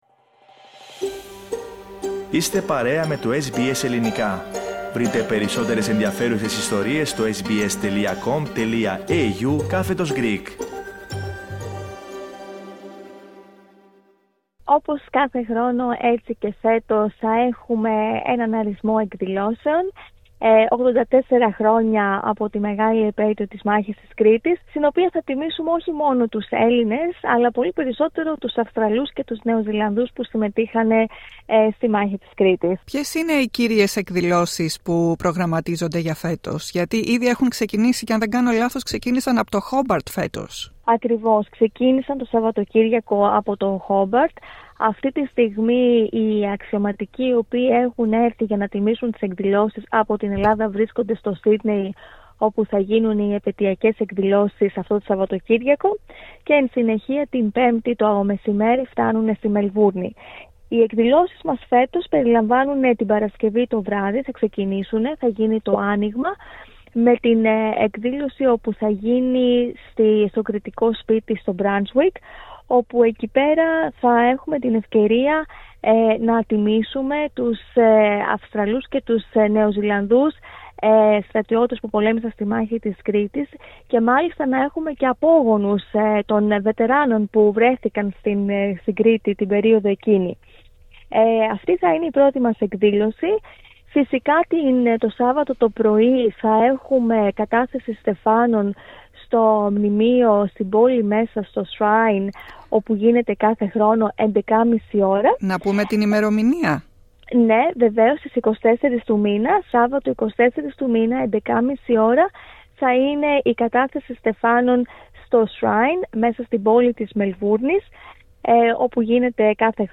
μίλησε στο Ελληνικό Πρόγραμμα σχετικά με τις φετινές εκδηλώσεις στη Μελβούρνη